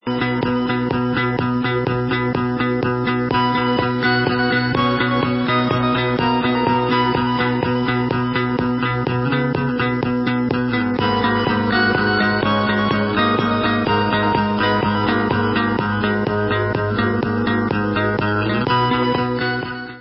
sledovat novinky v kategorii Dance